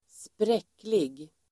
Ladda ner uttalet
spräcklig adjektiv, speckled Uttal: [²spr'ek:lig] Böjningar: spräckligt, spräckliga Definition: med fläckar i olika färger, fläckig dappled adjektiv, spräcklig , fläckig mottled adjektiv, fläckig , spräcklig